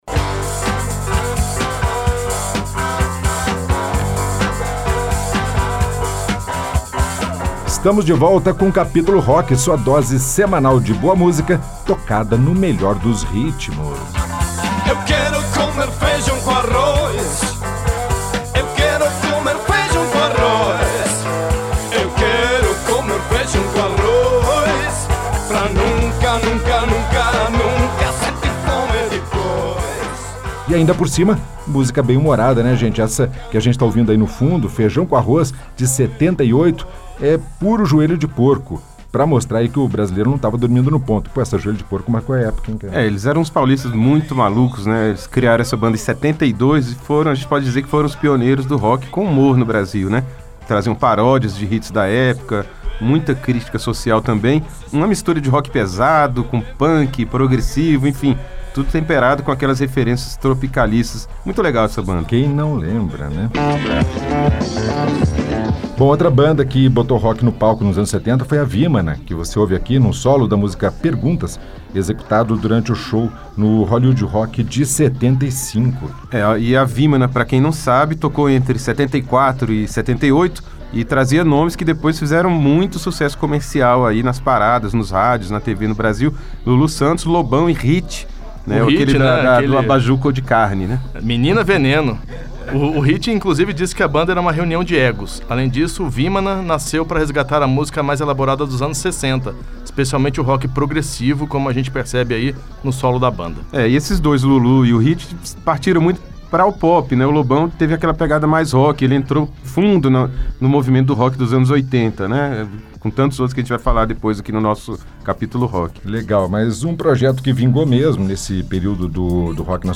O Capítulo Rock vai trazer neste programa o rock feito na segunda metade da década de 70. Foi a época em que o rock se profissionalizou de verdade, e construiu a maioria dos grandes ídolos.